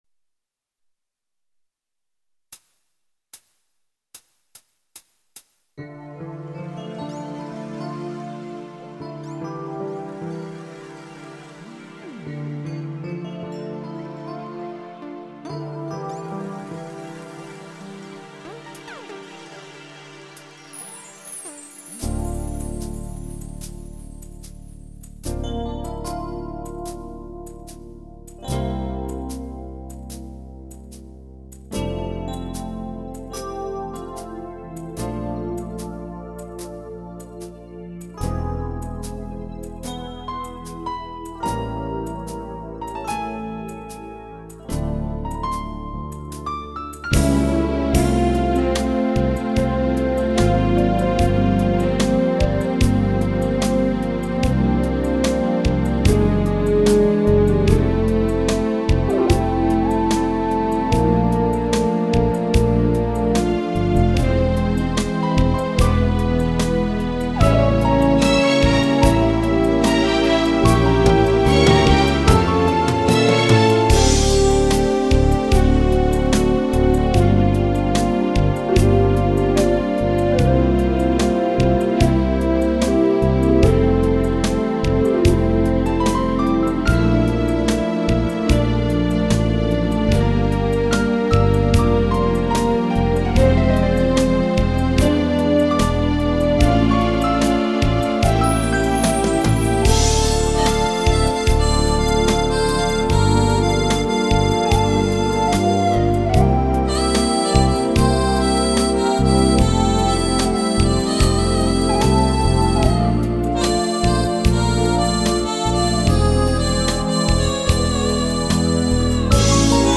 СПС , за обьективное замечание , во первых , конечно да , есть рассинхронизация , потому-что , я только на одном треке поставил этот ми-ми-ре-ре-до-до-си, и на нем же подобрал гитарный арпеджио, напоминающий отдаленно гитарный бой , вообще по уму , нужны отдельные треки для соло гитары , ритм ,и...